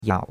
yao3.mp3